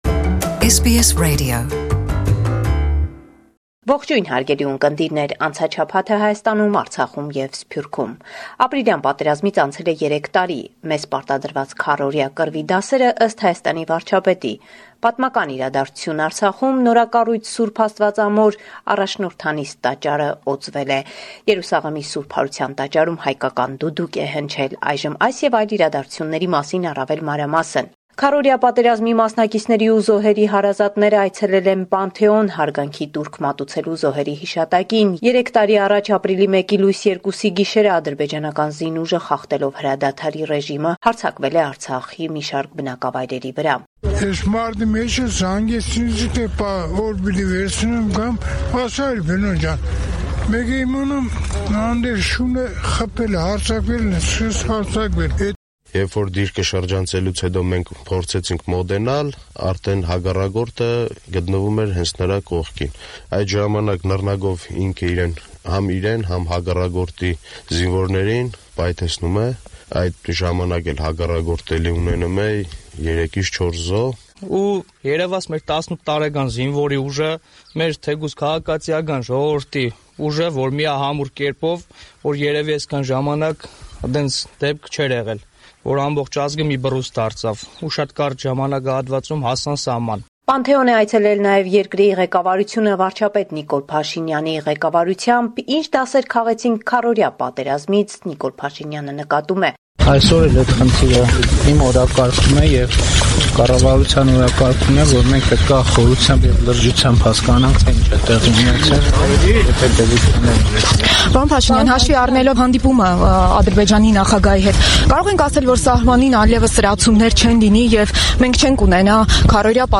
Latest News - 9 April 2019
News from Armenia, Artsakh and the Diaspora from our reporter